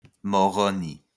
Moroni (French pronunciation: [mɔʁɔni]